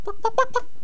chicken_select4.wav